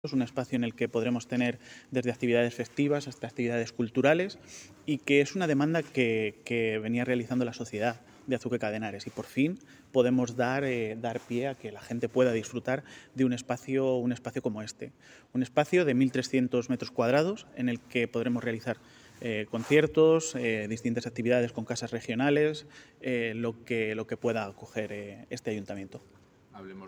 Declaraciones del alcalde Miguel Óscar Aparicio 2